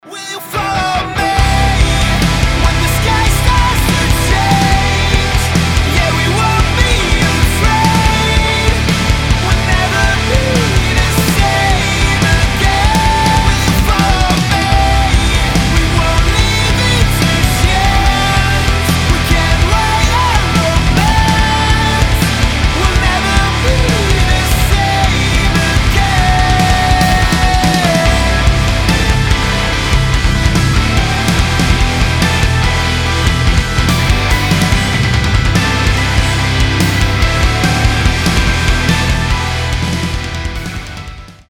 • Качество: 320, Stereo
мужской вокал
громкие
Alternative Metal
Alternative Rock